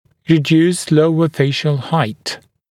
[rɪ’djuːst ‘ləuə ‘feɪʃl haɪt][ри’дйу:ст ‘лоуэ ‘фэйшл хайт]уменьшенная высота нижнего отдела лица